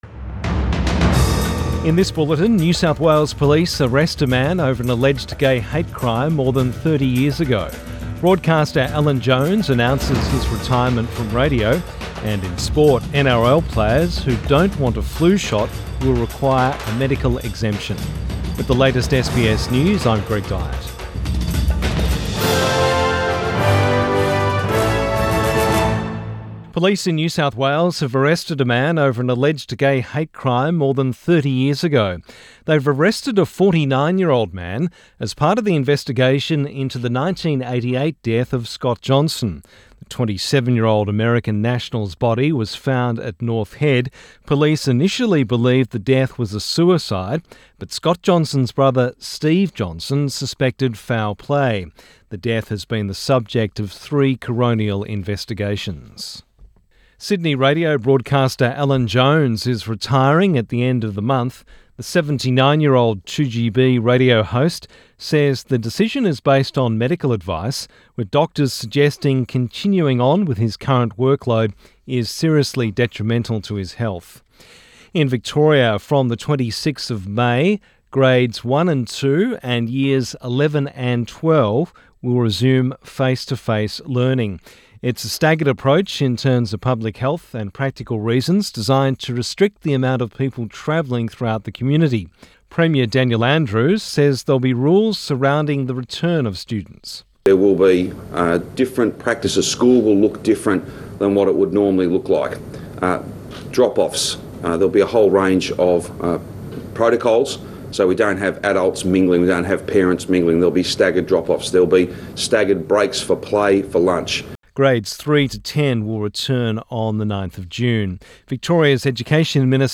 Midday bulletin 12 May 2020